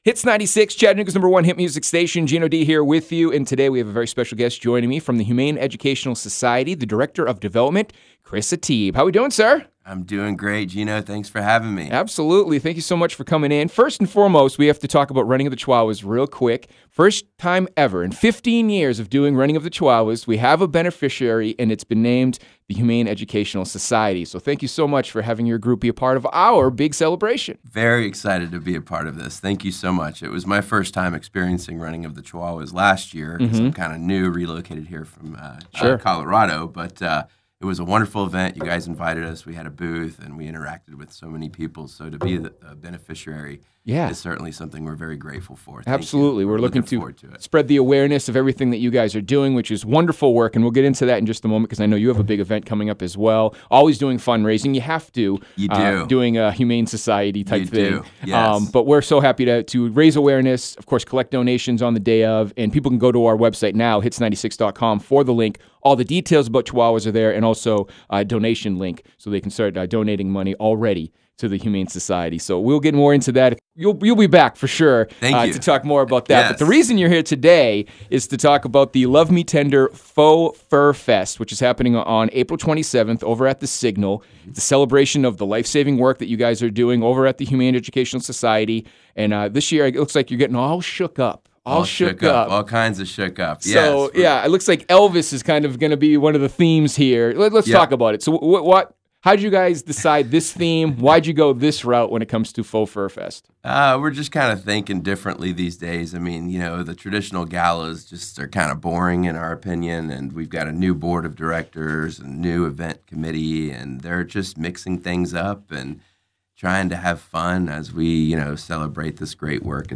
HES-CHATT-Full-Interview.wav